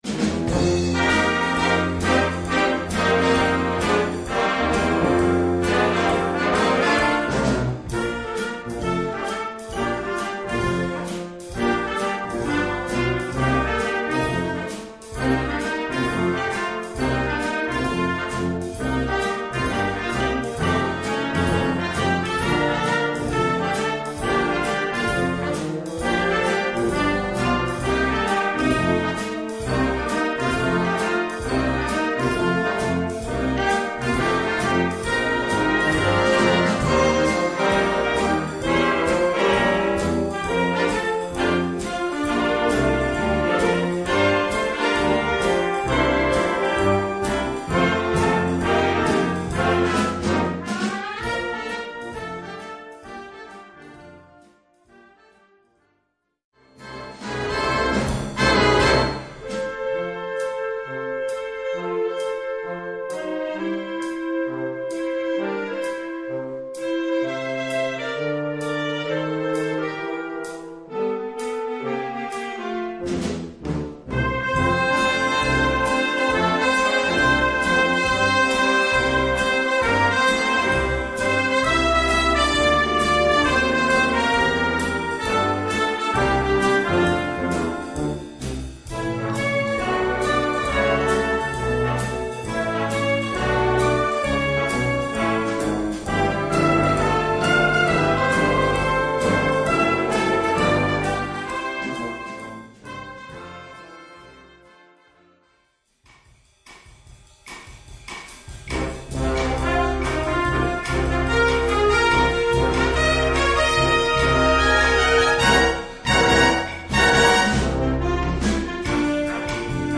Gattung: Musik für Weihnachten
Besetzung: Blasorchester